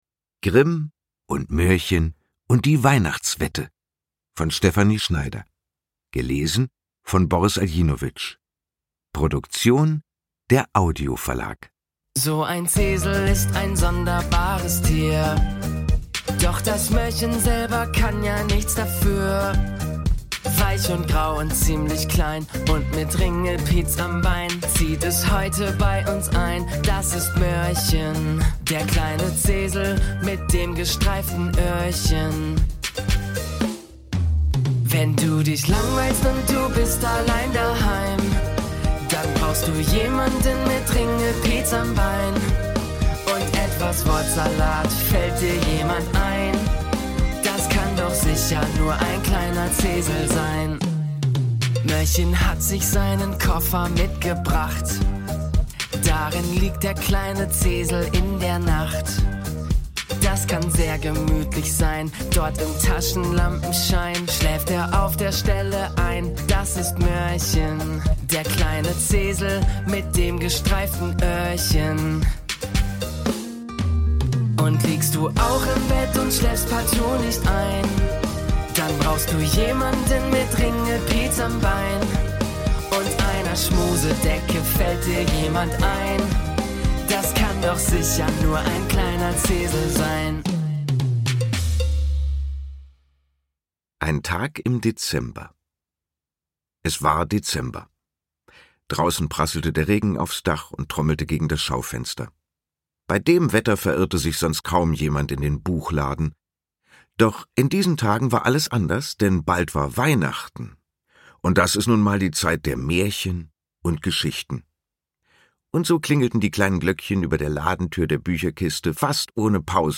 Lesung mit Musik mit Boris Aljinović (2 CDs)
Boris Aljinović (Sprecher)
»Voller (Wort-)Witz und Wärme gelesen von Boris Aljinović.« Eltern family